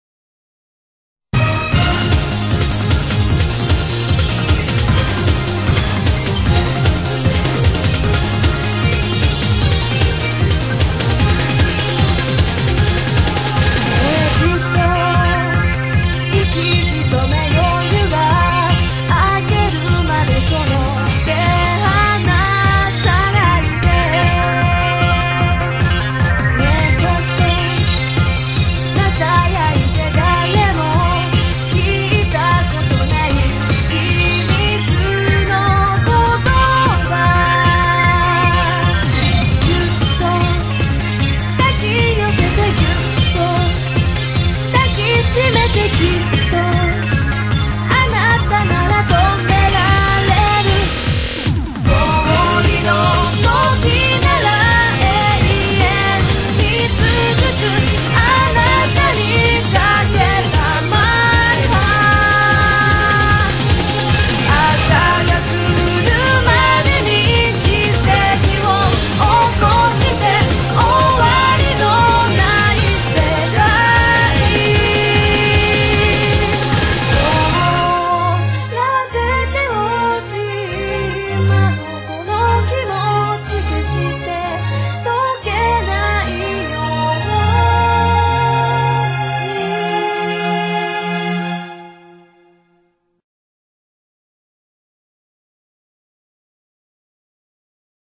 BPM152
Genre: HAPPY J-EURO